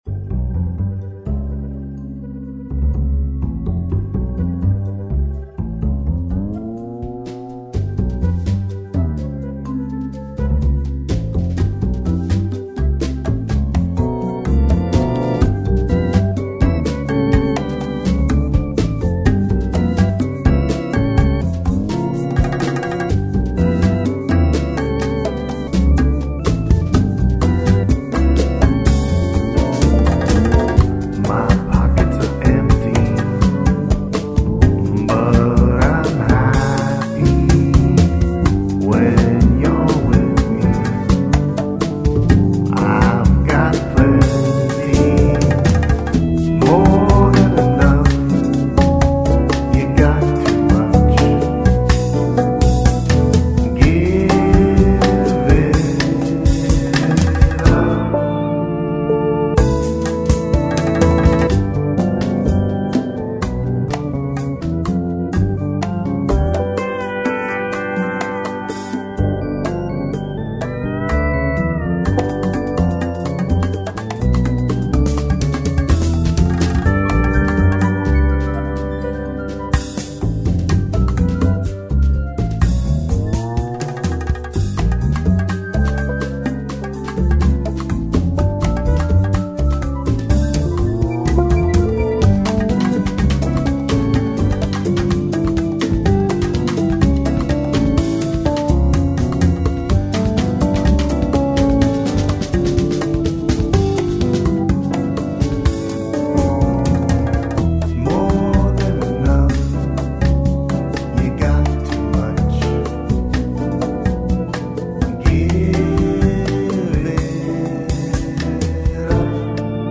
_____a tune in the G overtone mode